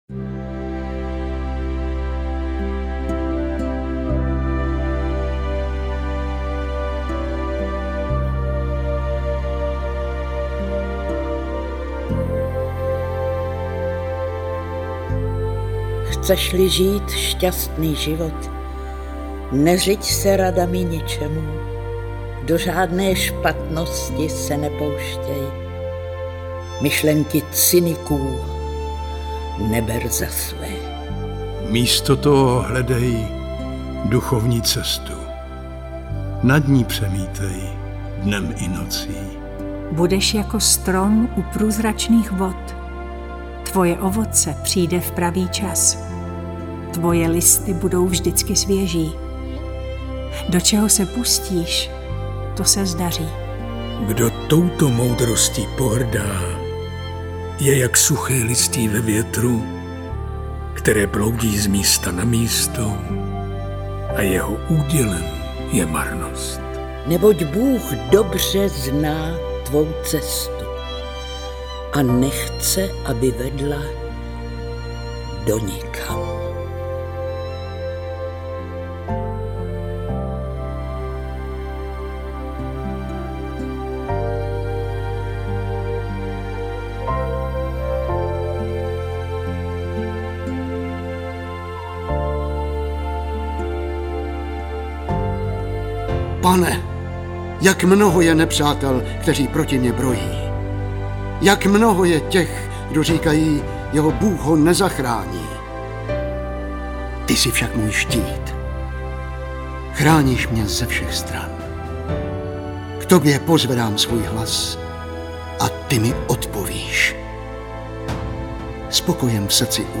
Audiokniha: Žalmy – David a jeho blues
Přebásnění zvýrazňuje právě tuto jejich stránku, dává prostor emocím, jež vyznívají přesvědčivě v podání známých českých herců. V mnoha případech je zvolena rytmizovaná forma, jež koresponduje s originální hudbou.
Všechno dohromady vyznívá jako velkolepá slavnostní bohoslužba.
Nahrávka vznikla na podzim roku 2015 ve studiích DAMU, postprodukce následovala na jaře 2016 ve studiu BITT.
Herci, kteří v nahrávce vystupují: Věra Kubánková, Petr Pelzer, Jan Vlasák, Viktor Preiss, Jaromír Meduna, Taťjana Medvecká, Ivan Trojan, Lukáš Hlavica, Igor Bareš, Dana Černá, Petr Lněnička, Magdaléna Borová a Matouš Ruml.